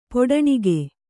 ♪ poḍaṇige